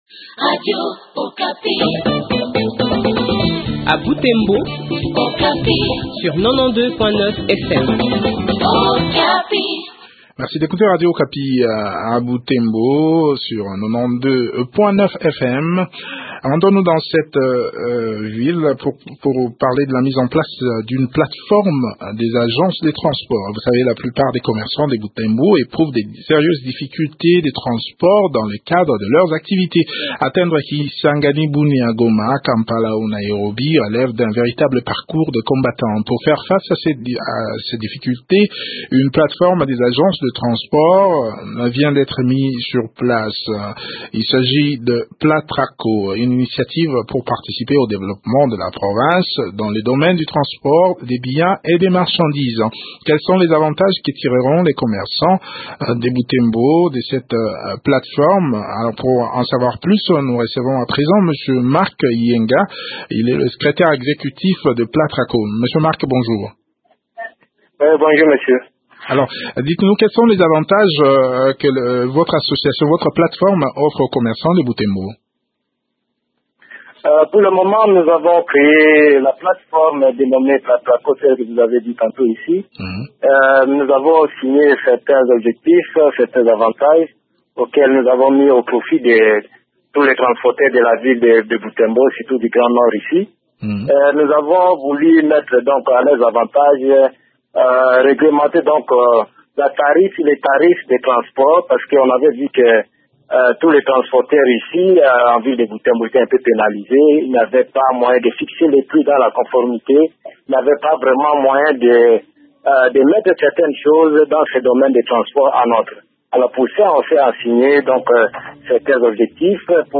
fait le point de leurs activités au micro de